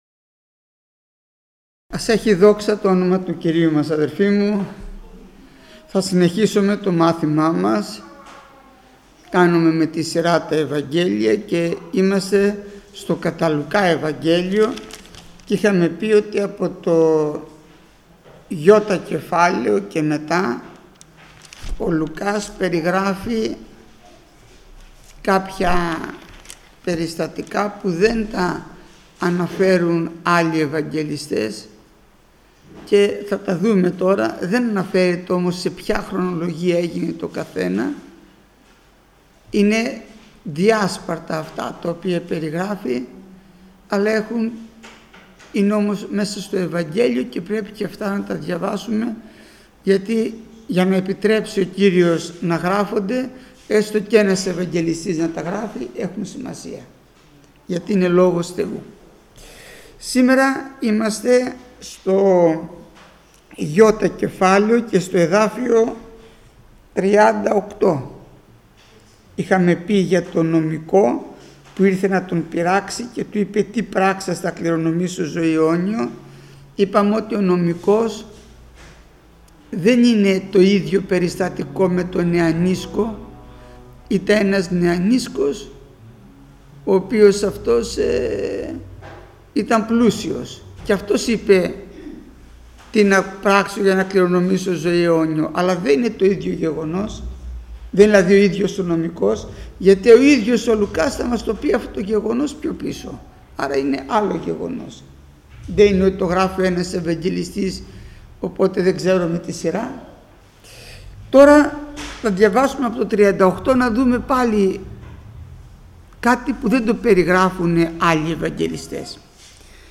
Μάθημα 475ο Γεννηθήτω το θέλημά σου